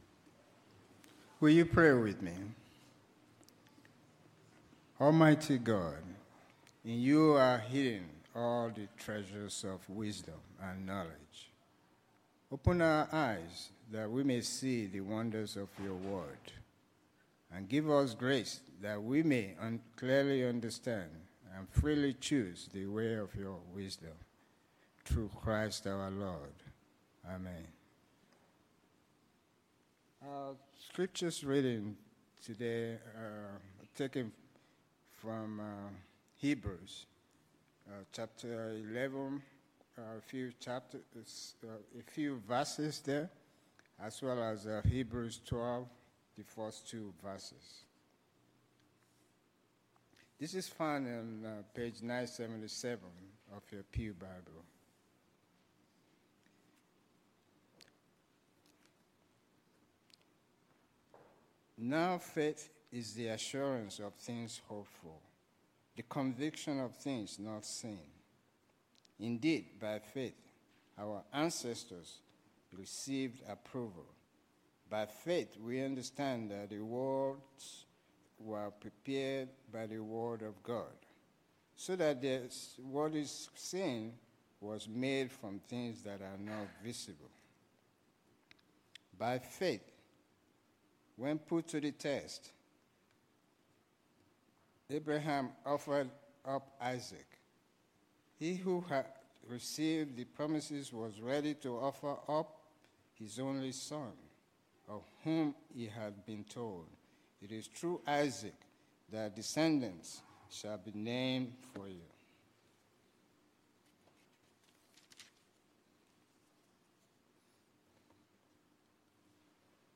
Knox Pasadena Sermons